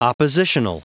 Prononciation du mot oppositional en anglais (fichier audio)
oppositional.wav